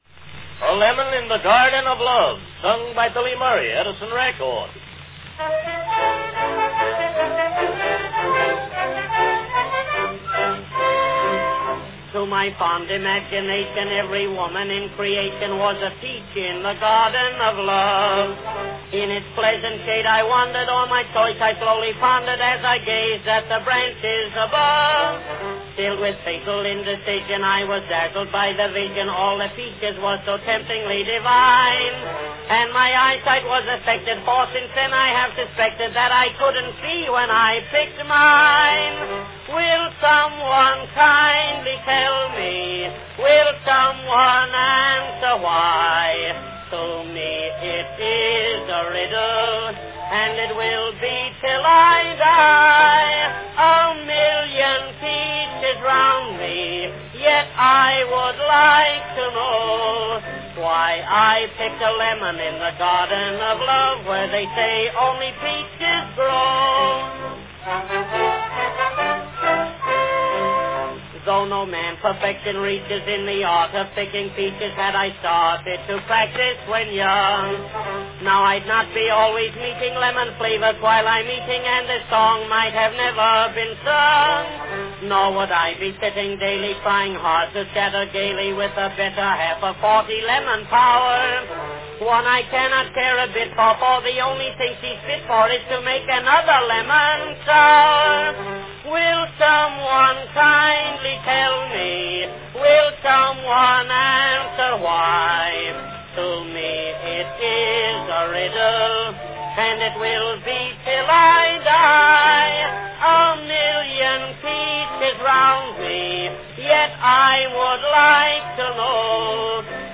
Billy Murray sings a hit comic song of 1907, A Lemon in the Garden of Love.
Category Comic song
Performed by Billy Murray
Announcement "A Lemon in the Garden of Love, sung by Billy Murray.   Edison record."
Mr. Murray sings with orchestra accompaniment.